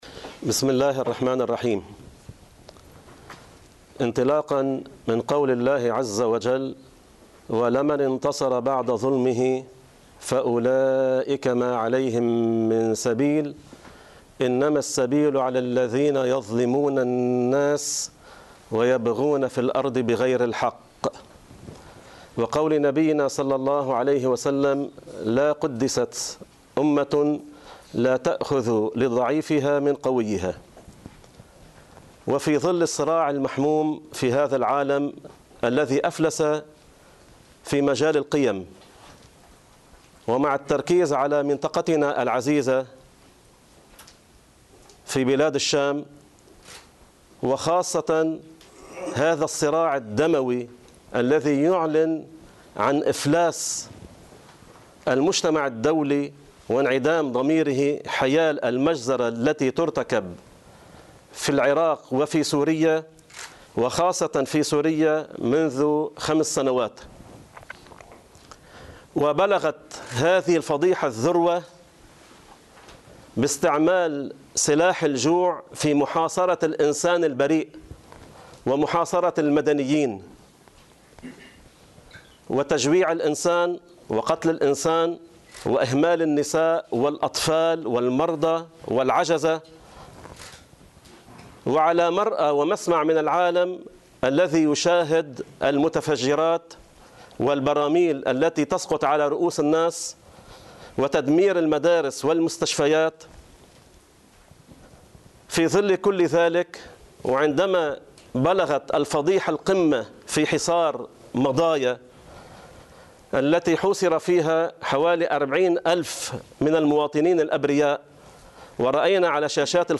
مؤتمر صحفي لهيئة علماء المسلمين حول: مضايا، سماحة، والموقوفين الإسلاميين